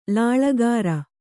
♪ lāḷagara